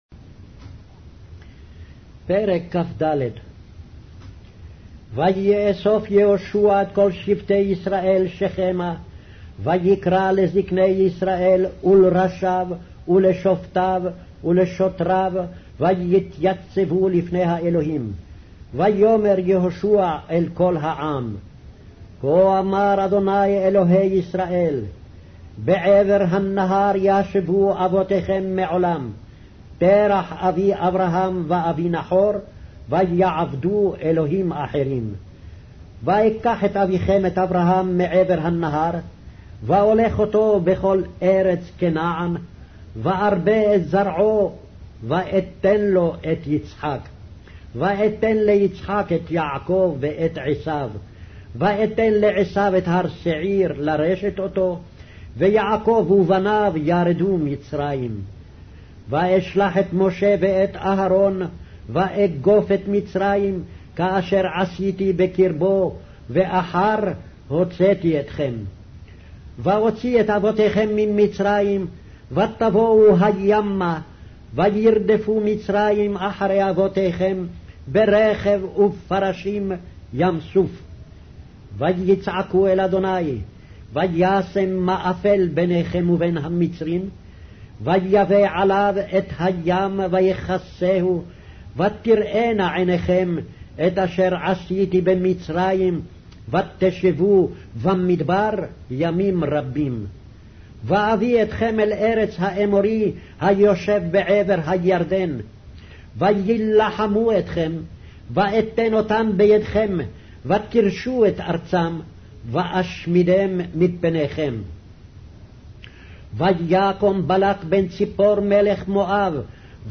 Hebrew Audio Bible - Joshua 16 in Ervta bible version